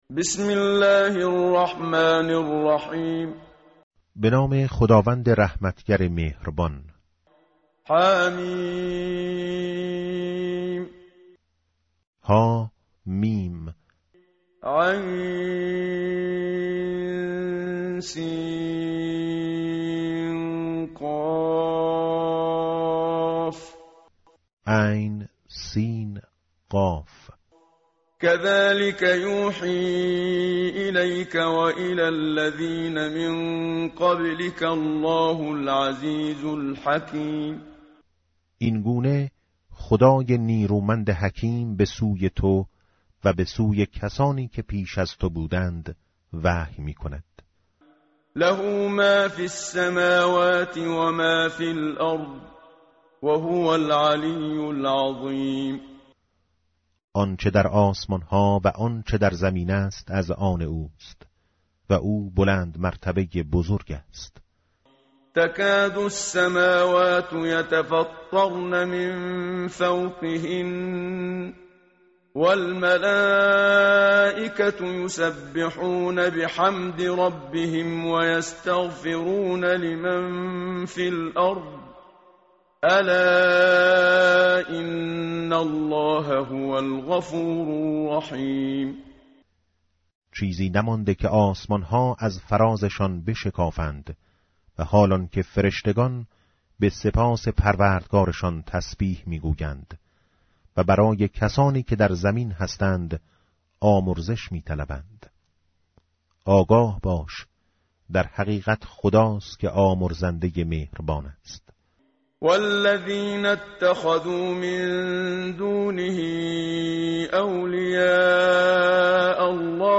متن قرآن همراه باتلاوت قرآن و ترجمه
tartil_menshavi va tarjome_Page_483.mp3